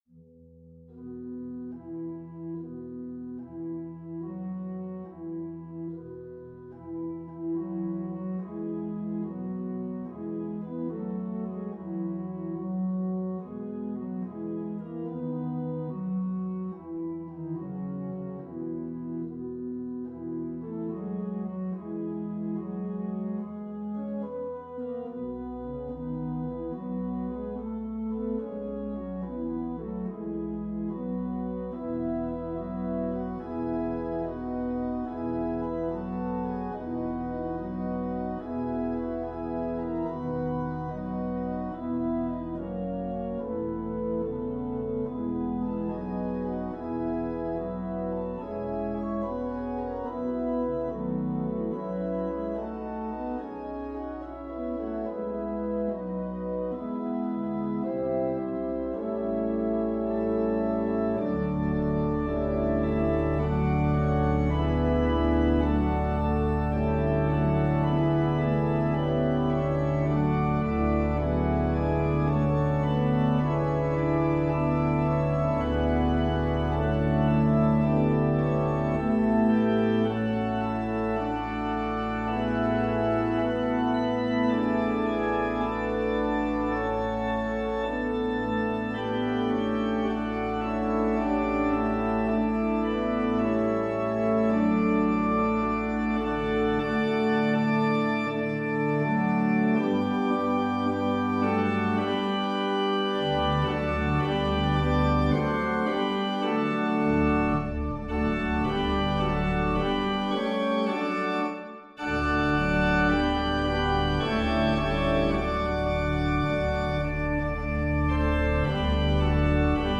organ solo
Voicing/Instrumentation: Organ/Organ Accompaniment